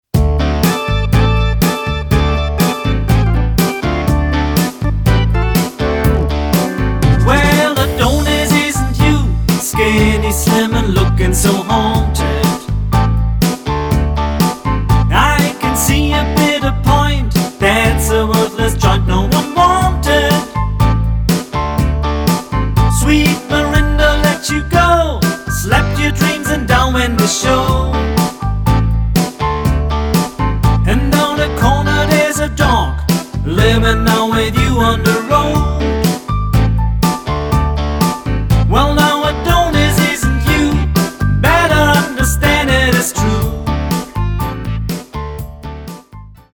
Singer-Songwriter-Album